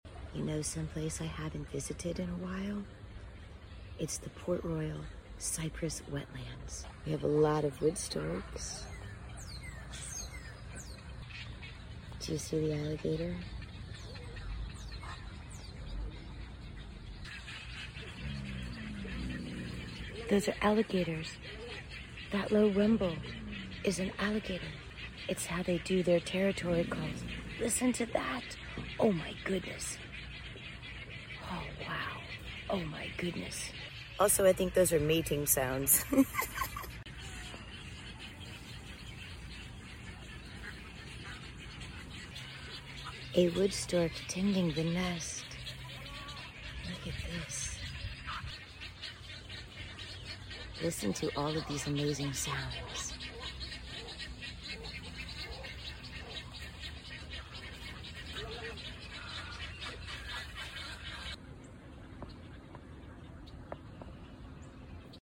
Have you ever heard an alligator bellow?